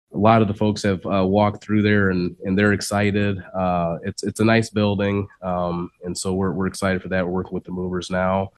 County Administrator Kevin Catlin says the time has finally arrived.